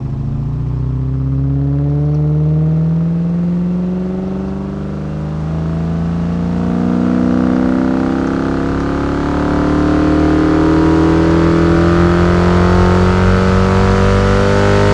rr3-assets/files/.depot/audio/Vehicles/v8_05/v8_05_Accel.wav
v8_05_Accel.wav